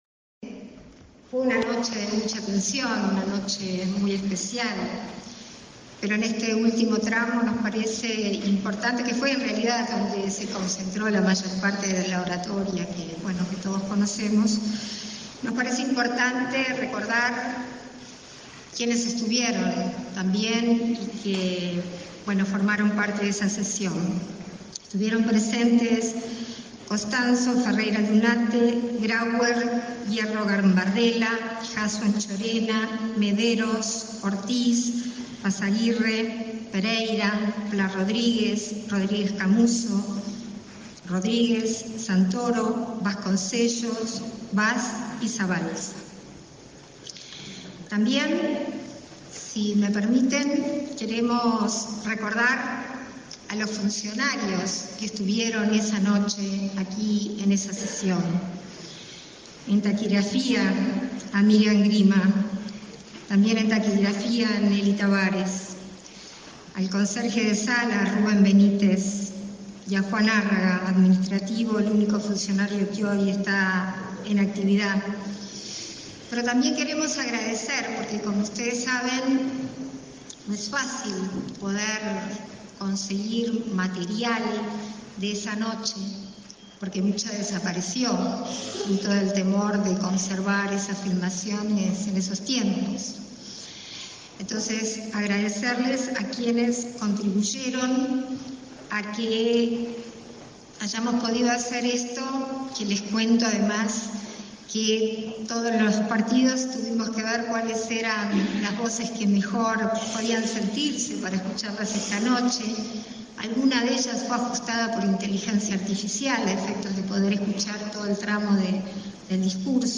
Palabras de la vicepresidenta de la República, Beatriz Argimón
Palabras de la vicepresidenta de la República, Beatriz Argimón 26/06/2023 Compartir Facebook X Copiar enlace WhatsApp LinkedIn La vicepresidenta de la República, Beatriz Argimón, disertó, este 26 de junio, en la recreación de la última sesión previa a la disolución de las Cámaras.